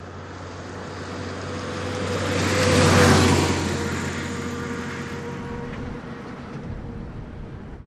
Truck, Hino Diesel, Pass By, Fast